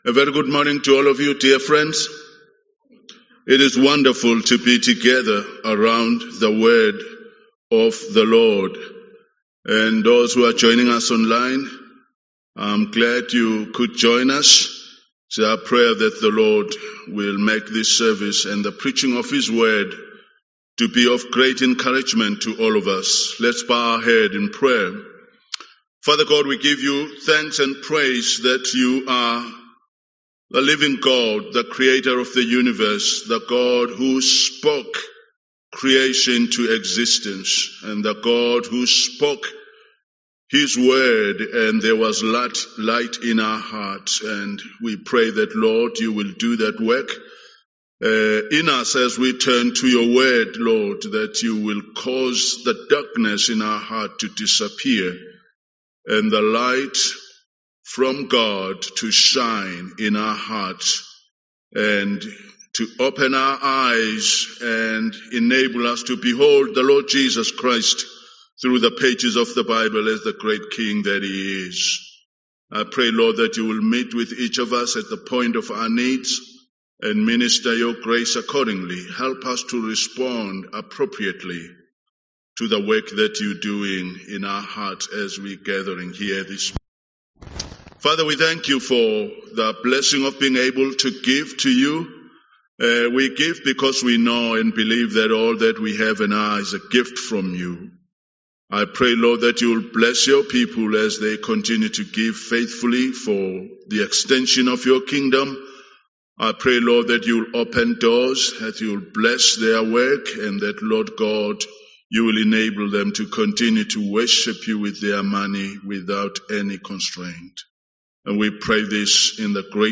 Matthew 4:12-25 Event: English Sermon Topics